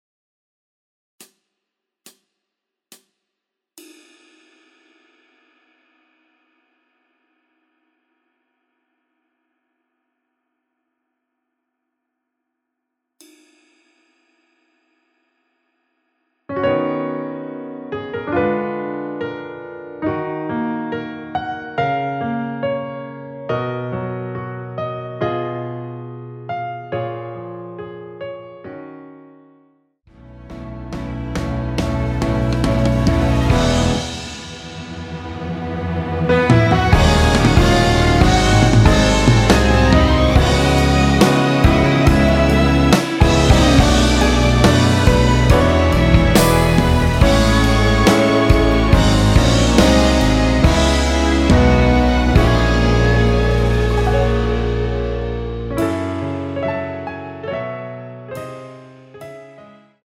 원키에서(-4)내린 MR입니다.
F#
앞부분30초, 뒷부분30초씩 편집해서 올려 드리고 있습니다.